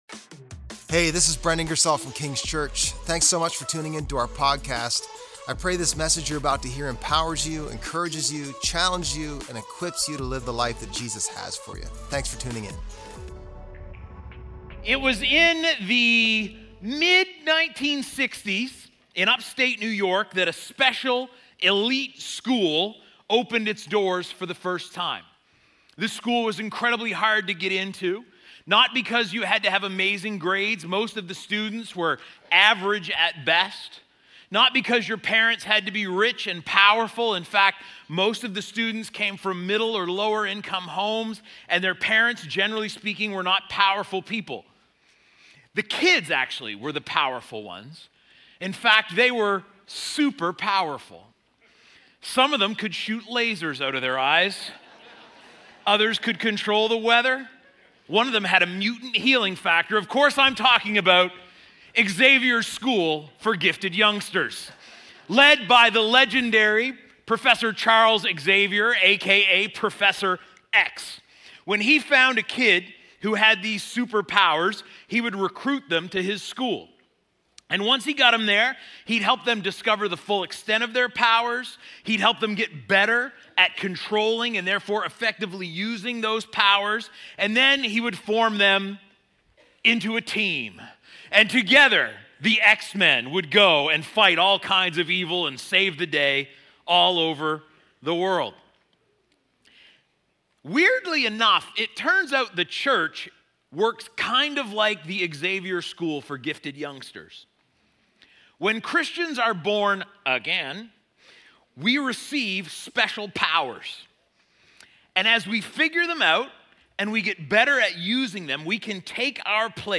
June_8_Sermon_Podcast.mp3